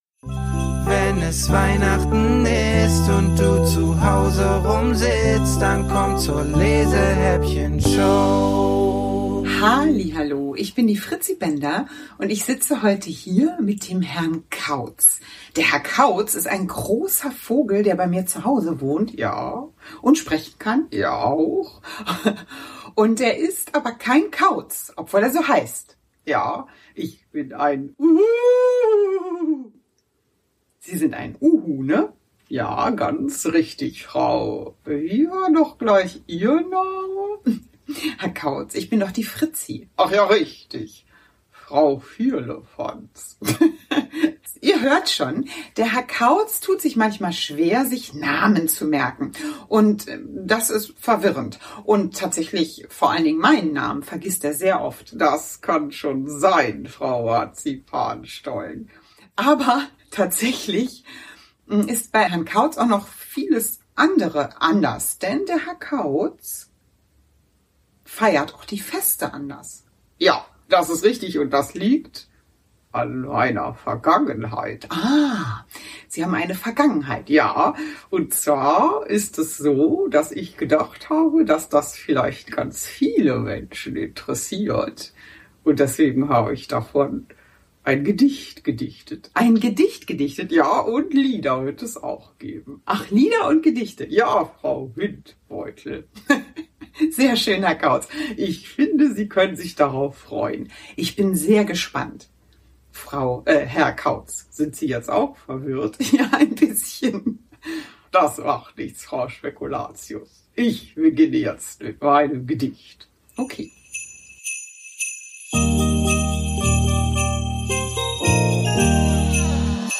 Autorinnen und Autoren höchstpersönlich vertont wurden!